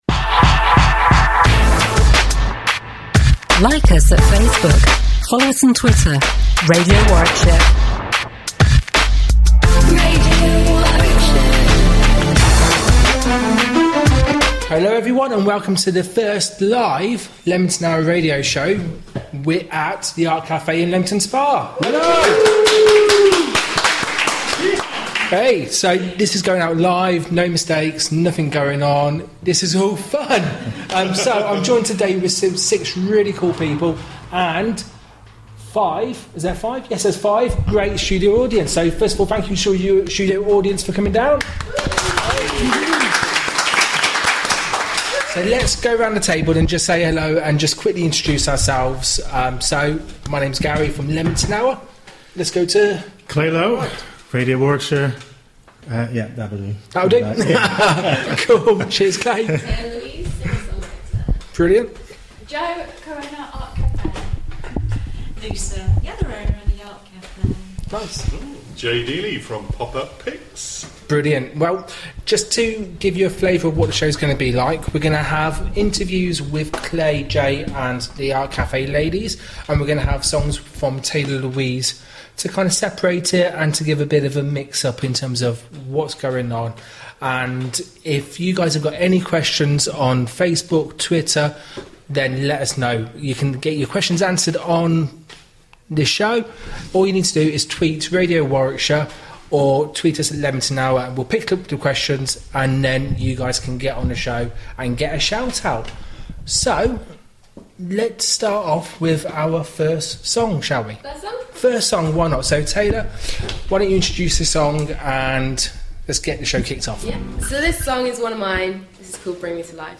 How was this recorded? This was the first LIVE radio show where we have interviews with: